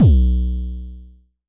Index of /m8-backup/M8/Samples/Drums/LookIMadeAThing Kicks - Vermona Kick Lancet/Distortion Kicks/Dist Kick - Precise
Dist Kicks 11 - B1.wav